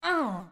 SFX_Mavka_Hit_Voice_01.wav